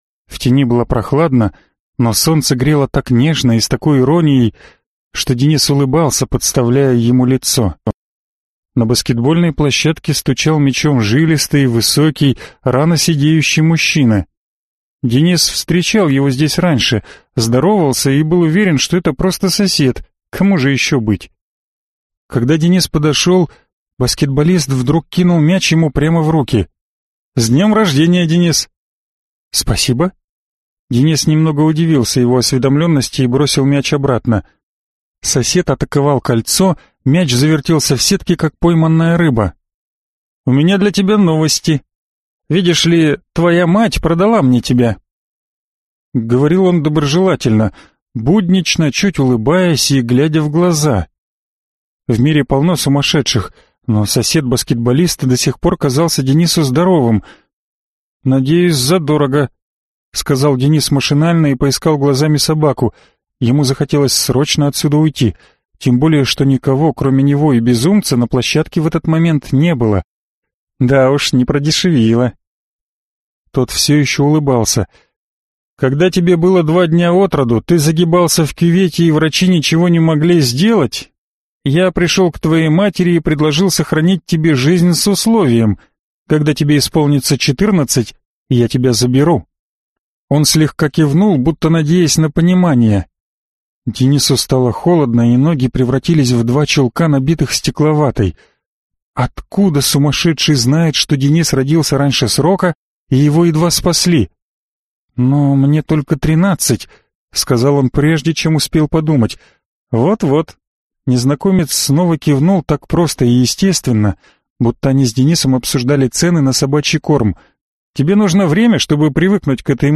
Аудиокнига Луч | Библиотека аудиокниг